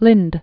(lĭnd), Jenny Known as "the Swedish Nightingale." 1820-1887.